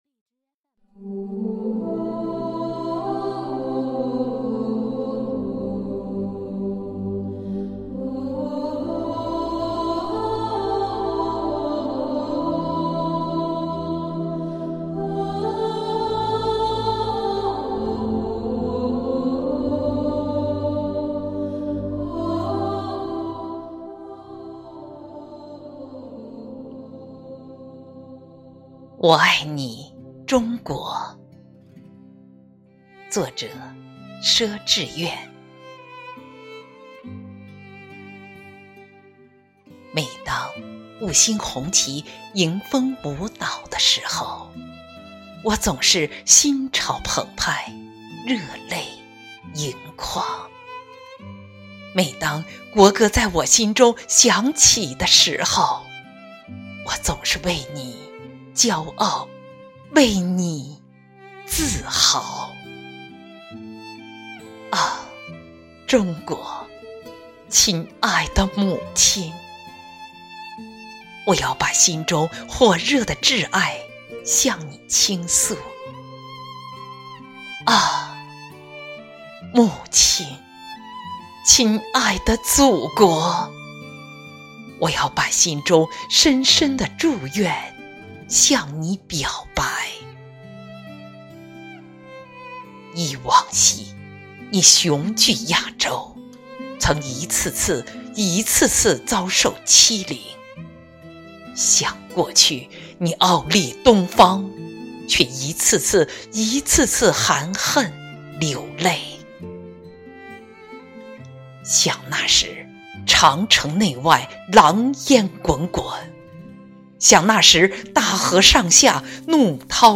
【诗朗诵】我爱你中国_都市头条，因你精彩！